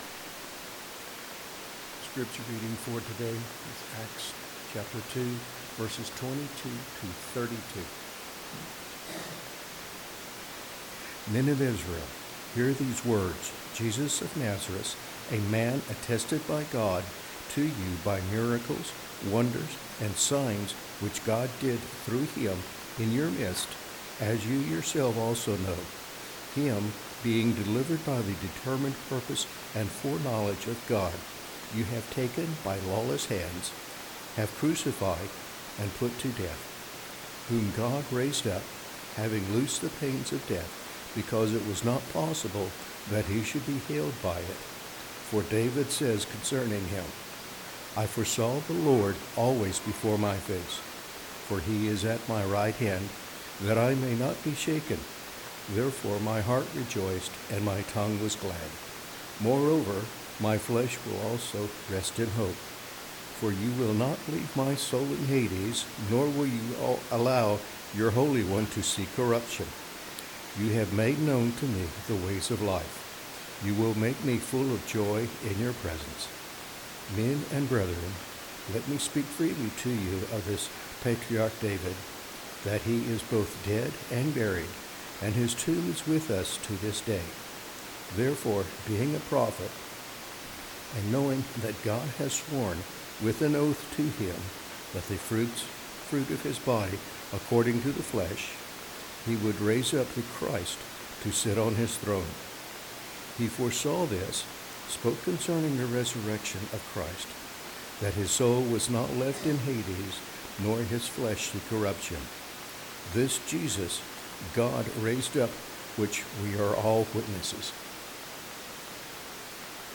Bible Text: Acts 2:22-32 | Preacher